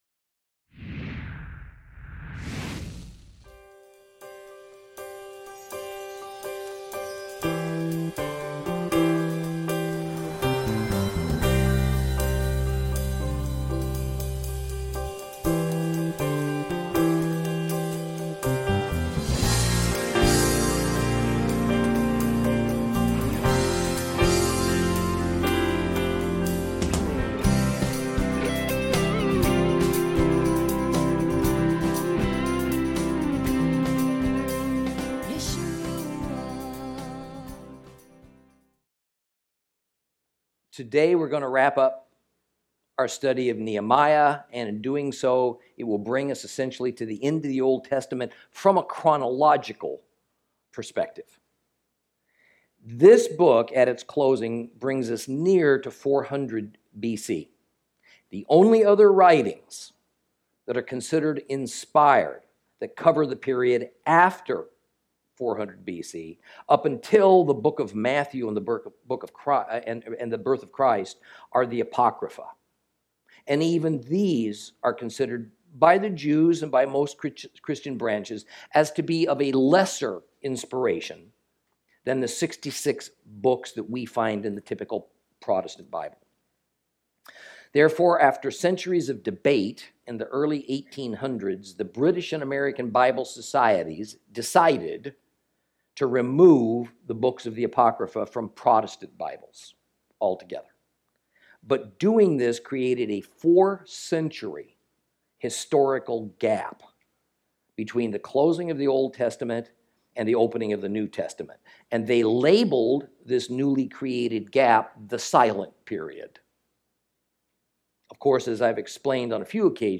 Lesson 19 Ch13 - Torah Class